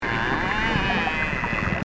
行為描述 無法得知。但群體共有4隻